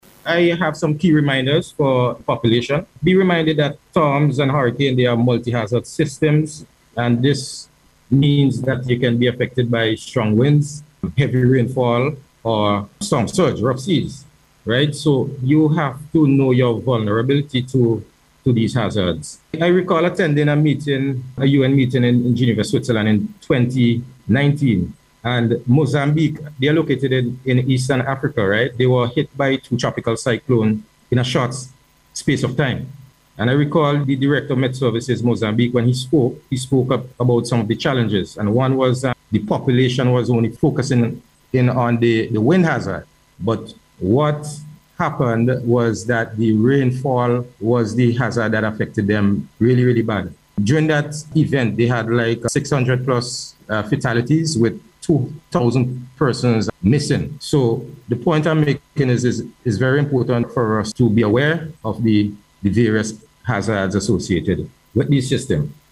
Speaking on NBC’s Face to Face programme on Monday